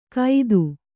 ♪ kaidu